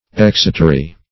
Search Result for " exotery" : The Collaborative International Dictionary of English v.0.48: Exotery \Ex"o*ter*y\, n.; pl.